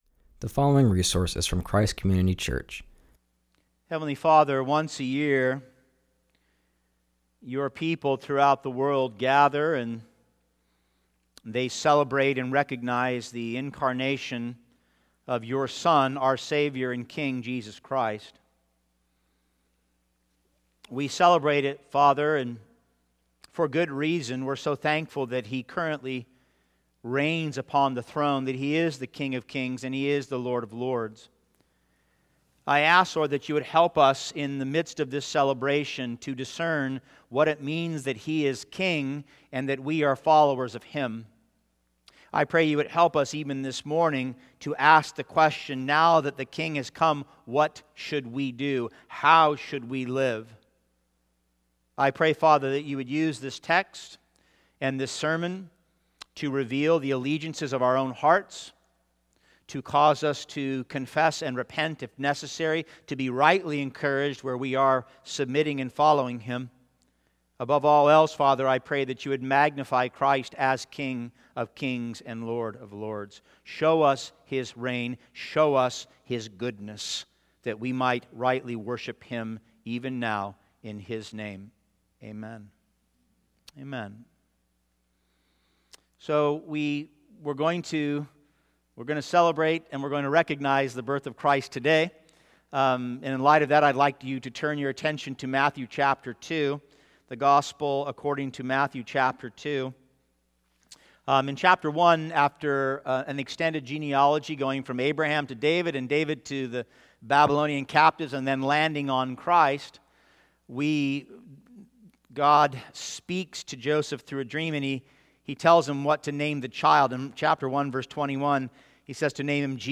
preaches on Matthew 2:1-12.